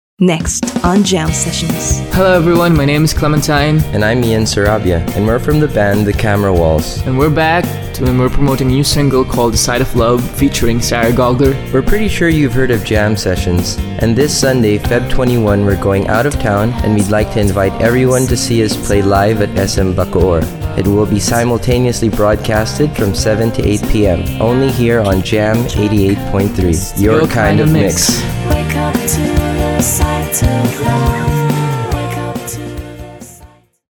jam-sessions-plug-the-camerawalls.mp3